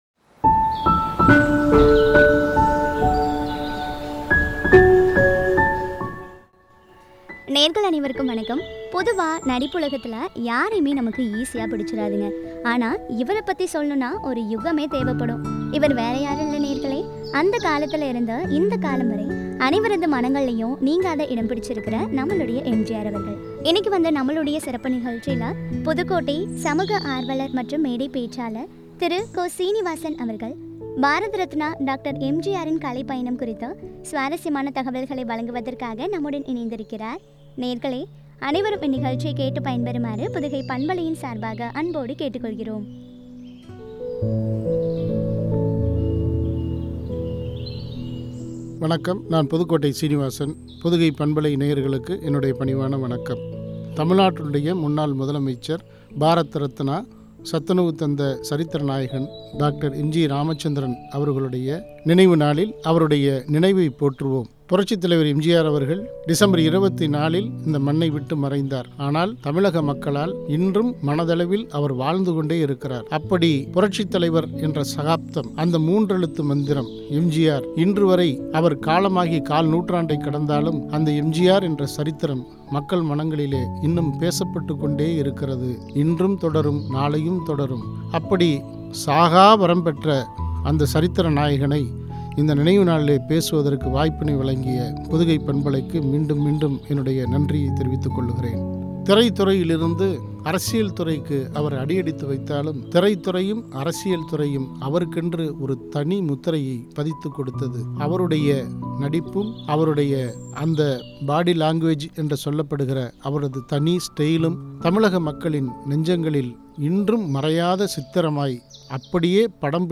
உரை.